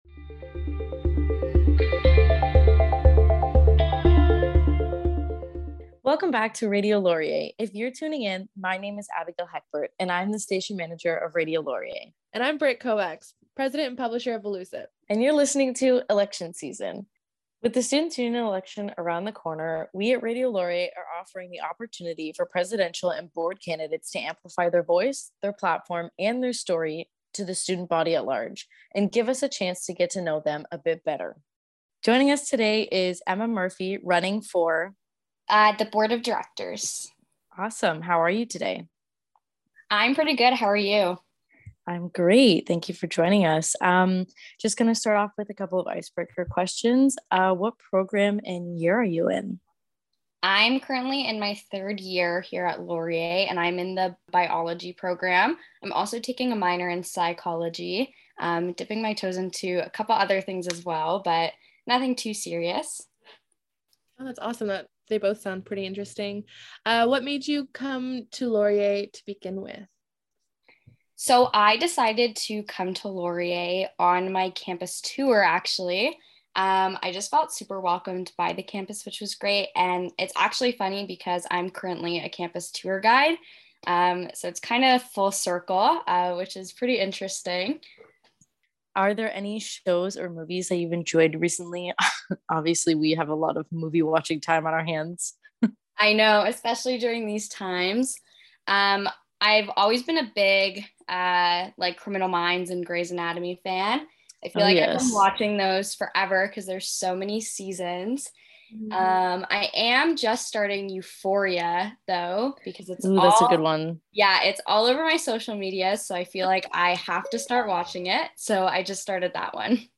Candidate Interview 2022